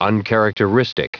Prononciation du mot uncharacteristic en anglais (fichier audio)
Prononciation du mot : uncharacteristic